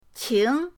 qing2.mp3